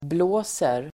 Uttal: [bl'å:ser]